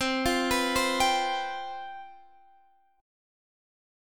CM7sus4 chord